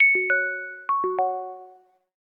Ding_Dong.ogg